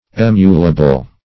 Search Result for " emulable" : The Collaborative International Dictionary of English v.0.48: Emulable \Em"u*la*ble\, a. [L. aemulari to emulate + -able.]